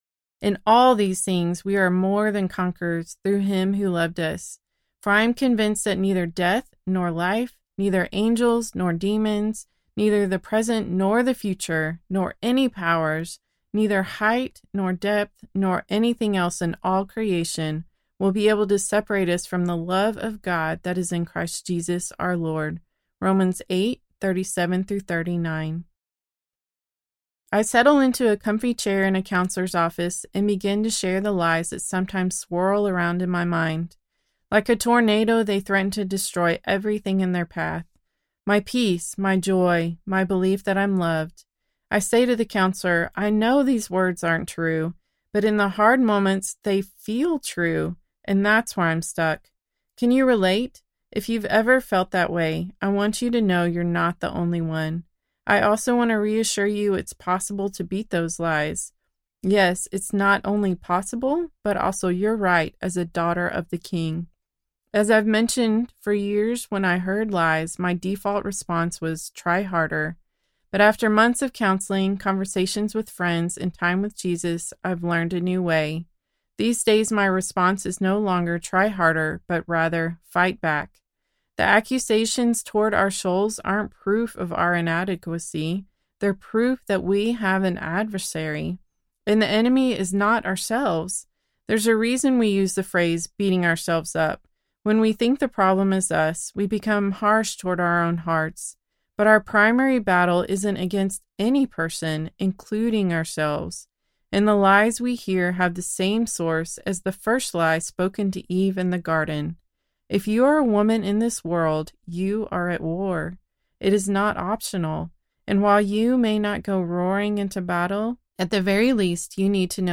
Strong, Brave, Loved Audiobook